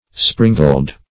(spr[i^]ng"ald), Springall \Spring"all\ (spr[i^]ng"al), n.